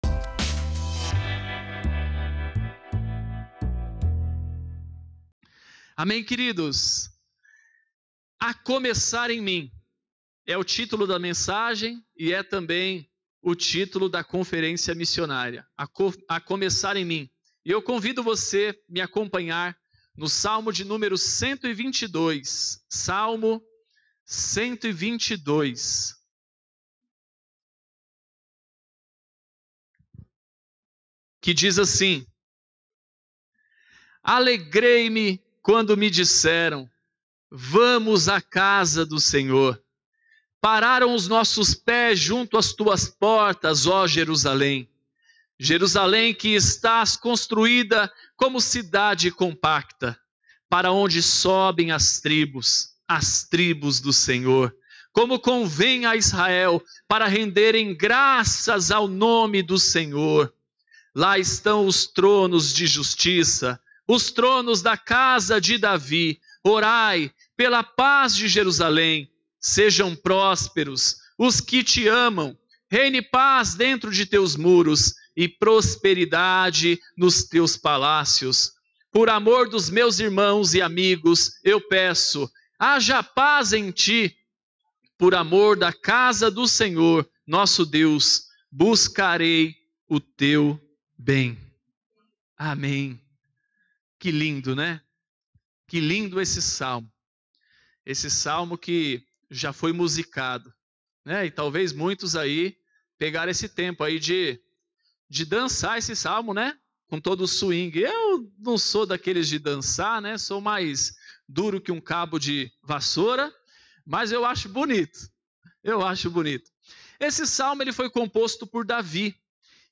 Mensagem ministrada